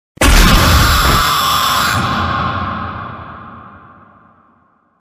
Fnaf 6 Jumpscare Meme sound effects free download